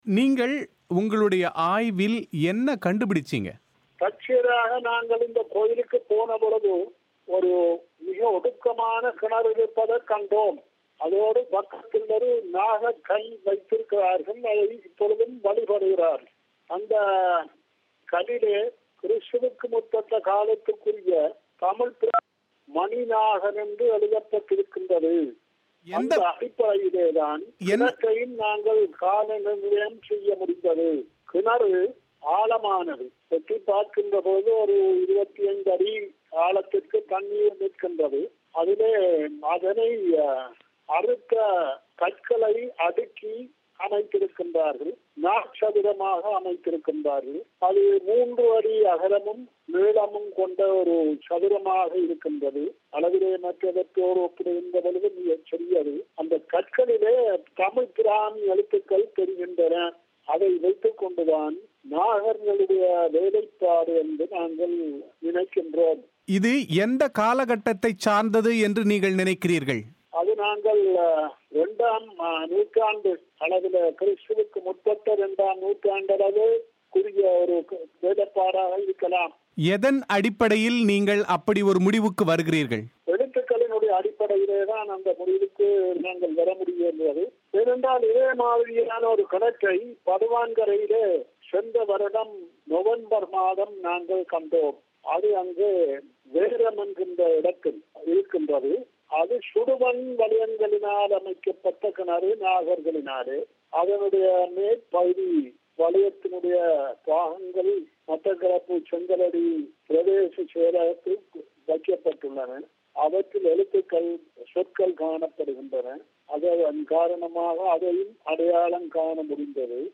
இது குறித்து அவர் பிபிசிக்கு அளித்த செவ்வியின் விரிவான ஒலி வடிவத்தை நேயர்கள் இங்கே கேட்கலாம்.